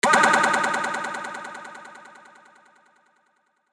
Вот Delay.